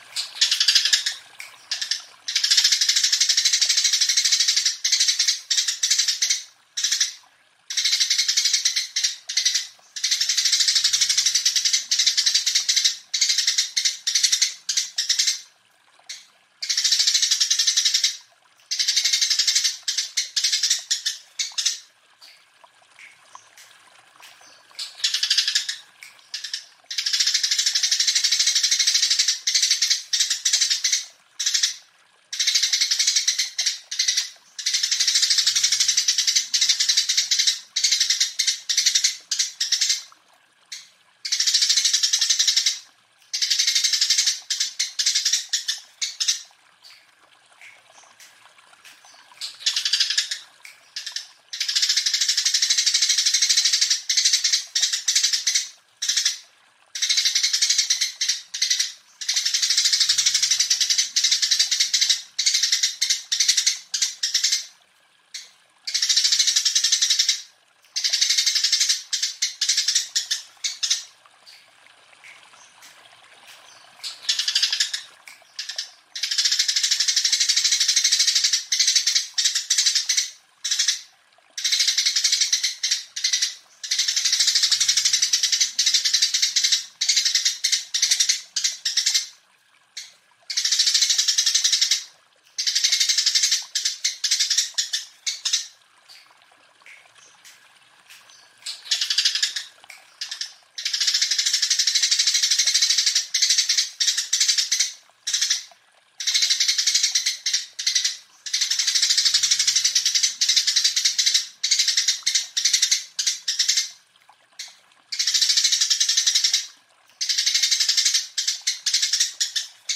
Dapatkan suara Cucak Cungkok vs Gereja gacor dan jernih dalam format MP3. Masteran dengan isian panjang dan jelas, ideal untuk melatih burung lebih gacor.
Suara Burung Cucak Cungkok vs Gereja
Suara tajam Cucak Cungkok dan merdu Gereja mempercepat proses pancingan suara, menjadikan burung Anda lebih aktif.
suara-burung-cucak-cungkok-vs-gereja-id-www_tiengdong_com.mp3